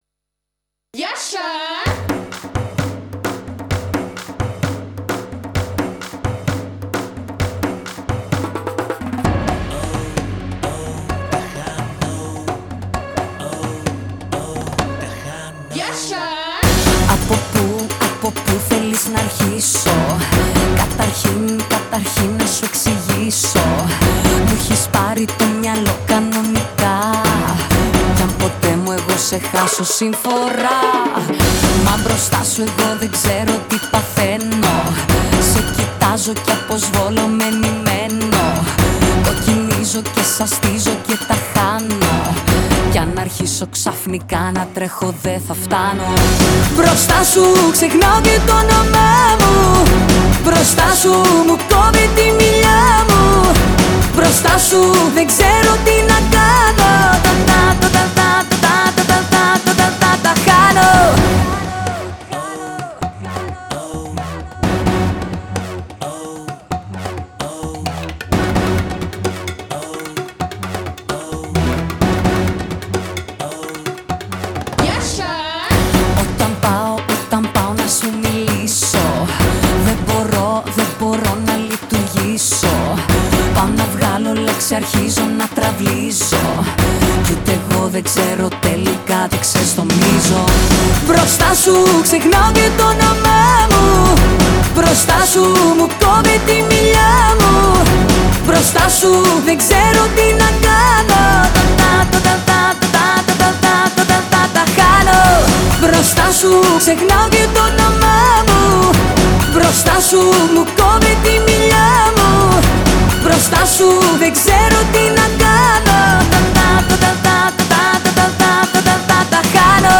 δίνει ρυθμό και ξεσηκώνει με ένα catchy τσιφτετέλι